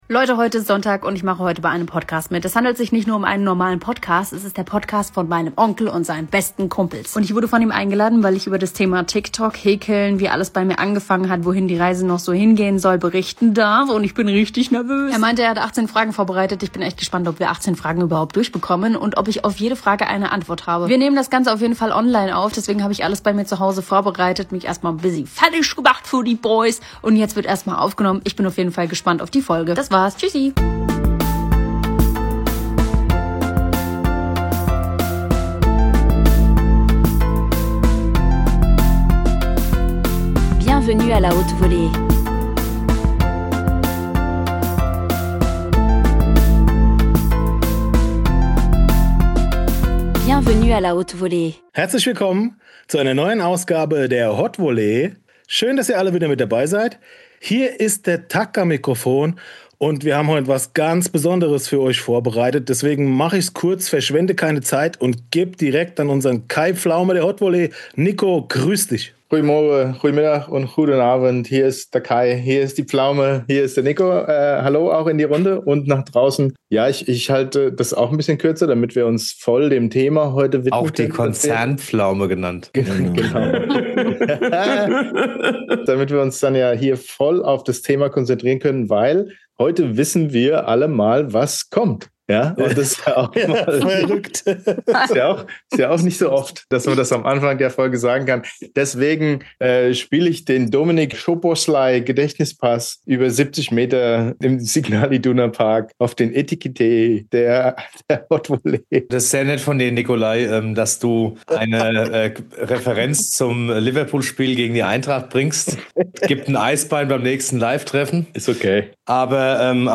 Folge 117 – TikTok, Häkeln & ein Interview.